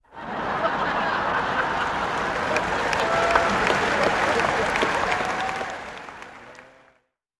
00:00 00:01 ������� ��Ч���ƣ� �˹���Ц��������(People Audience laugh ��Ч��ţ� yxiao-1635 ��Ч�ȶȣ� 0 �� �����Ч 1 [1600] ��������ѧ�Ļձ�4(Acoustic Log 2 ��ʥ��ħ����Ч 3 ֨֨��ı��ν����Ч 4 ��ɳ������һ��С���� 5 AK47��ǹ-ǹ֧